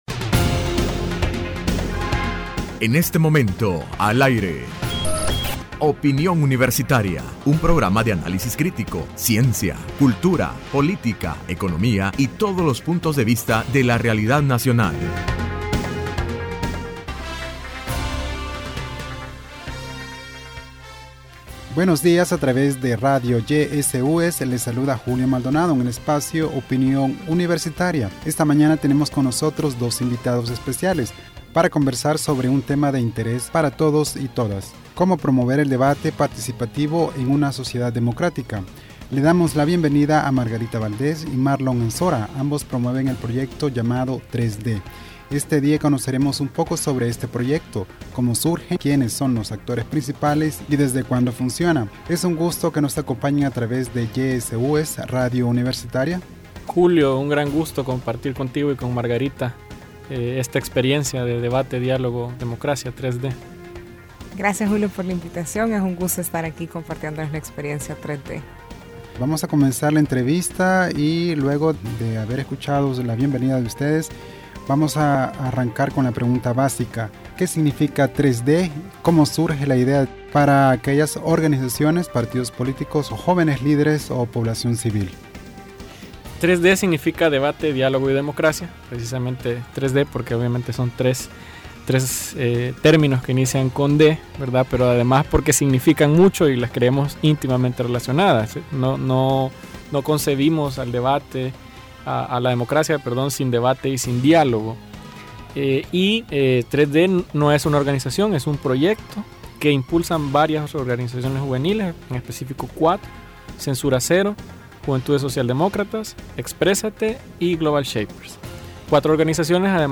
Entrevista Opinión Universitaria (27 de Julio 2015): Promover el debate participativo en la sociedad democrática.